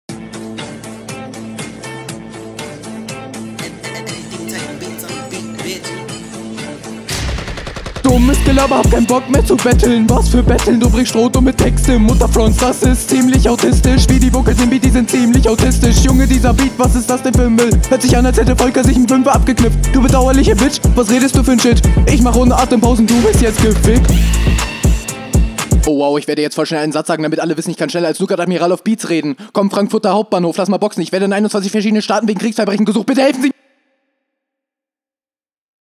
Battle Rap Bunker
Beat liegt dir ganz gut eigentlich, auch wenn der nicht so geil ist.